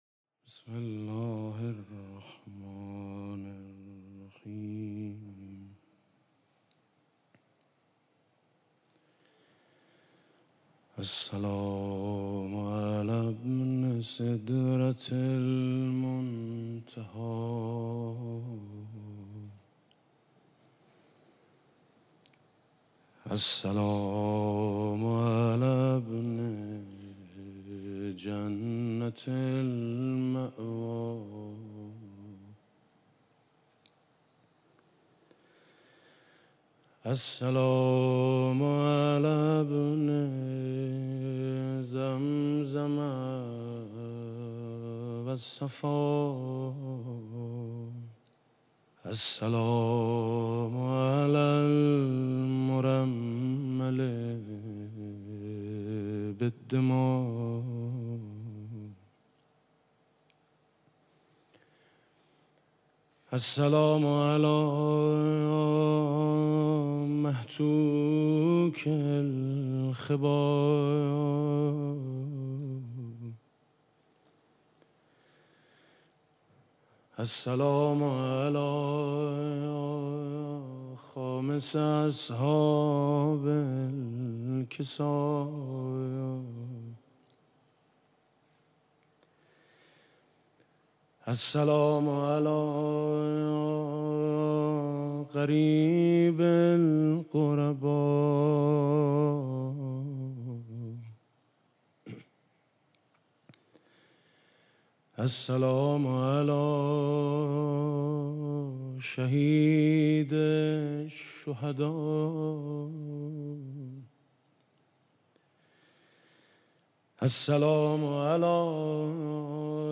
مراسم عزاداری و قرائت زیارت اربعین
قرائت زیارت اربعین و ذکر مصیبت توسط جناب آقای میثم مطیعی